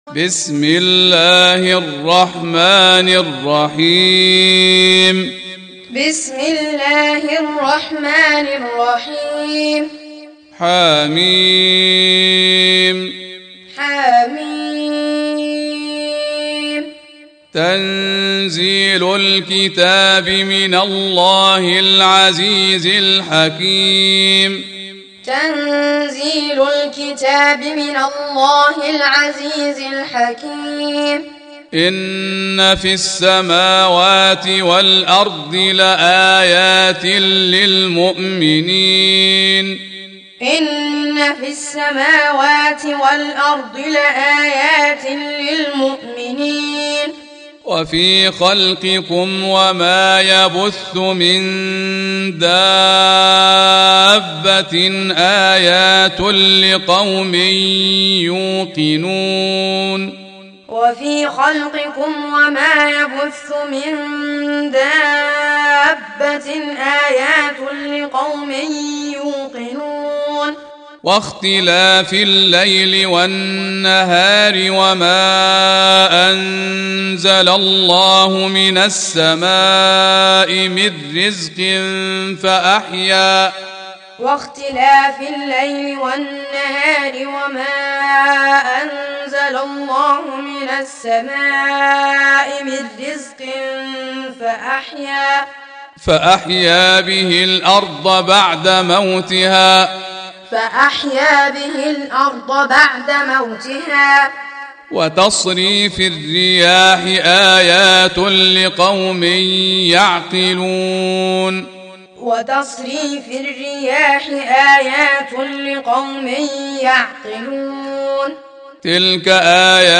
Audio Quran Taaleem Tutorial Recitation Teaching Qur'an
Surah Sequence تتابع السورة Download Surah حمّل السورة Reciting Muallamah Tutorial Audio for 45. Surah Al-J�thiya سورة الجاثية N.B *Surah Includes Al-Basmalah Reciters Sequents تتابع التلاوات Reciters Repeats تكرار التلاوات